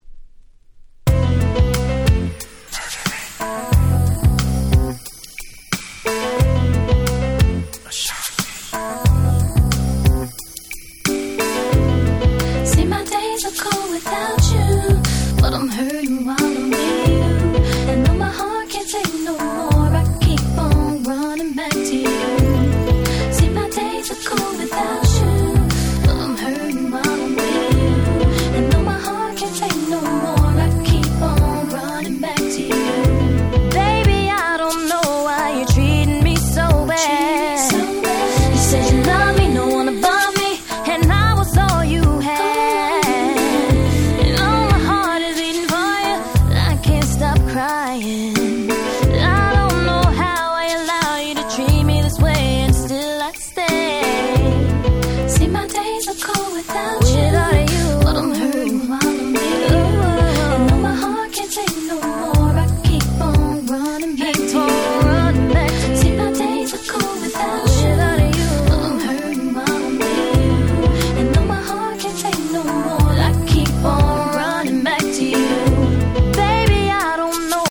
02' Super Hit R&B !!